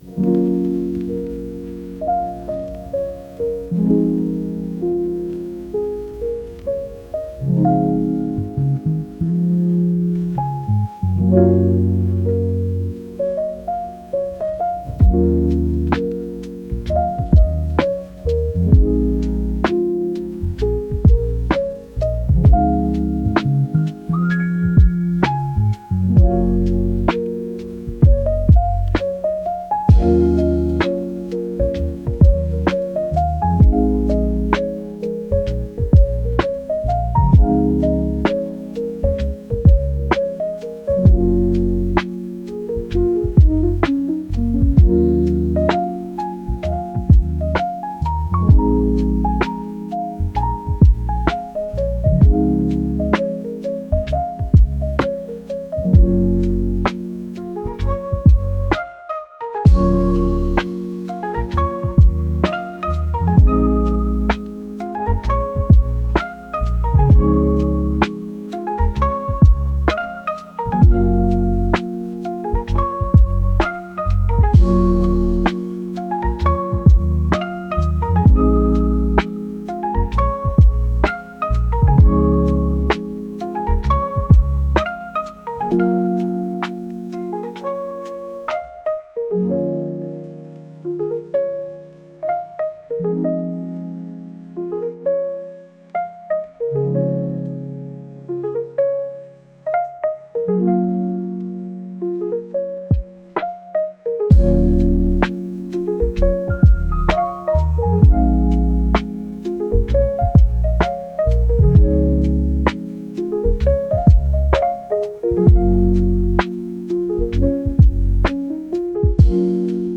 ゆったりとした穏やかなまどろむようなピアノ曲です。